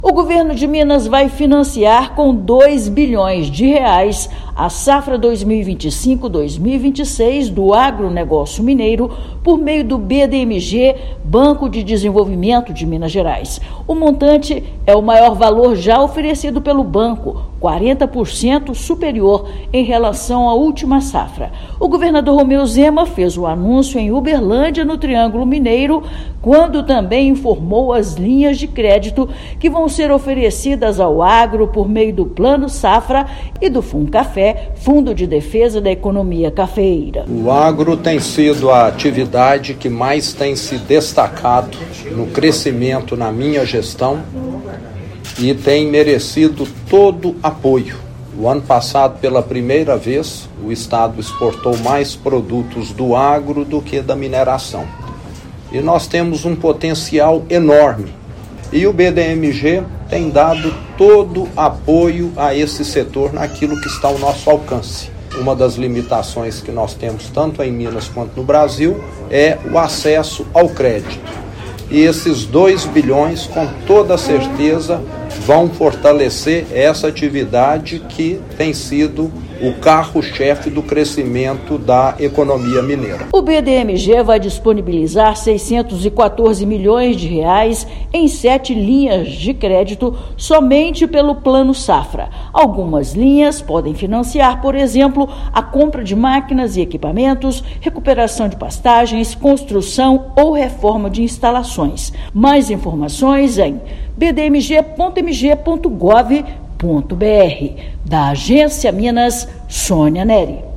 Por meio de linhas inéditas de financiamento, o BDMG vai apoiar produtores rurais, cooperativas e empresários de todo o estado. Ouça matéria de rádio.